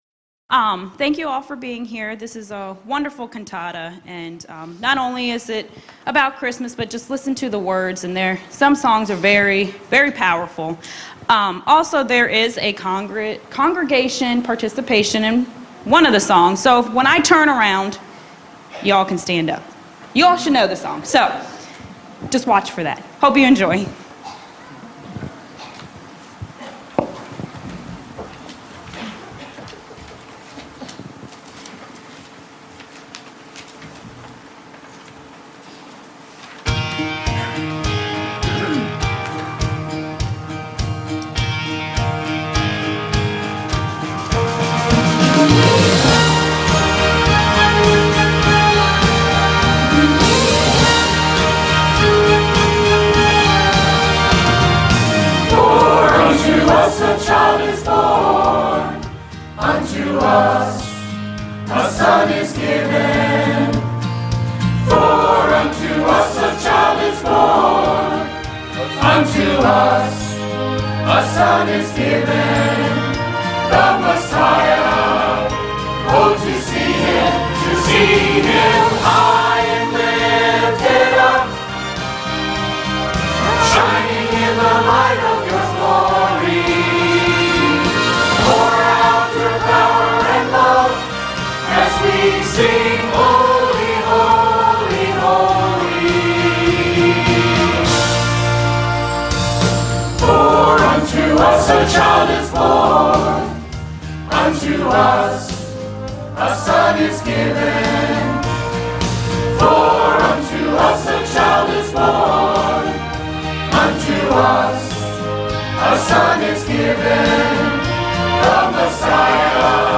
Choir